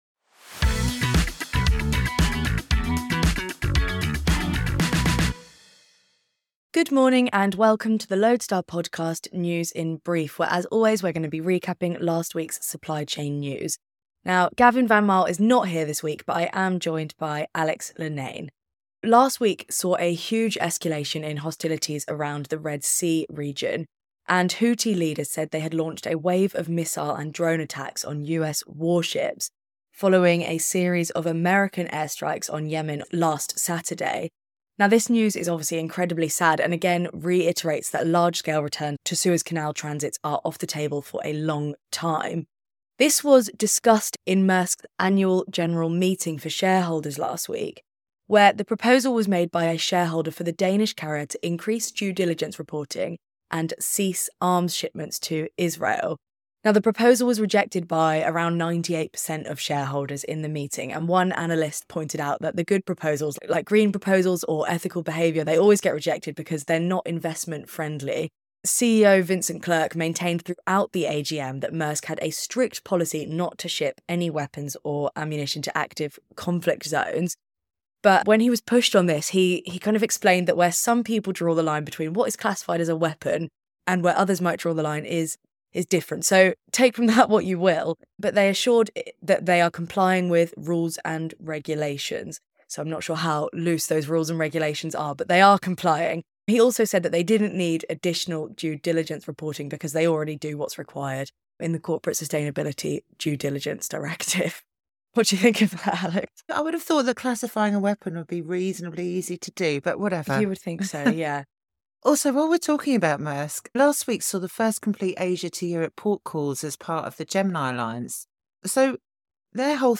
News in Brief Podcast | Week 12 | Geopolitics, Gemini and DOGE